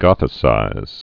(gŏthĭ-sīz)